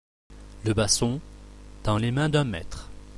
L’IMAGE DU JOUR – Basson
Podcast_Basson.mp3